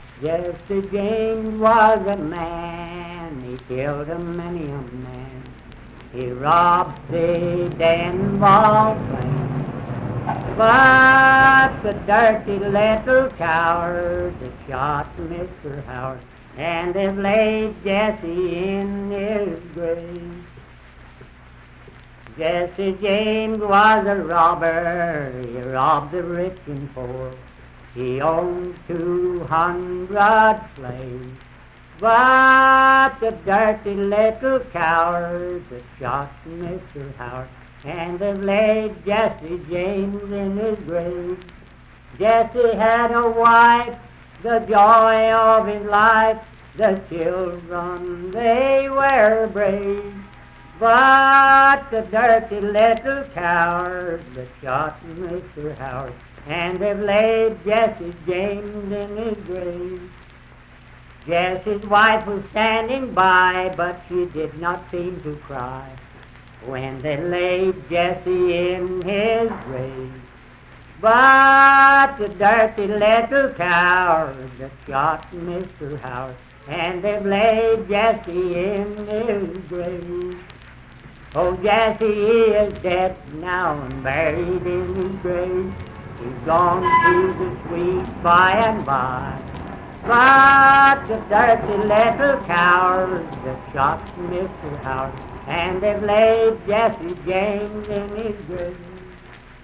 Voice (sung)
Parkersburg (W. Va.), Wood County (W. Va.)